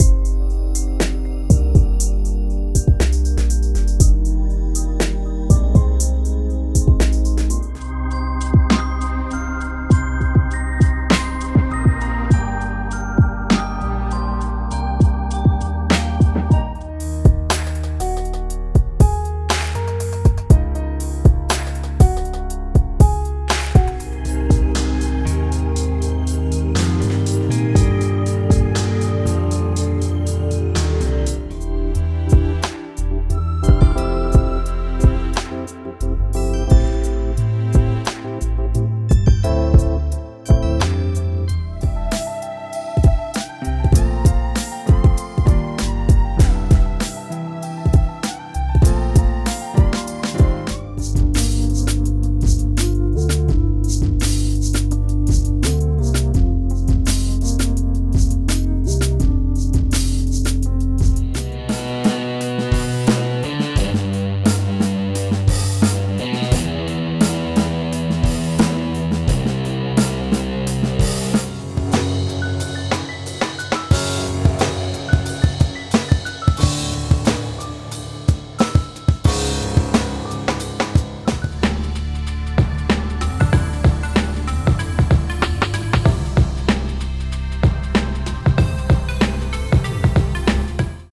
Lo-Fi
From airy melodies to laid-back rhythms
·     22 Melody & Chord Loops
·     11 Drum Breaks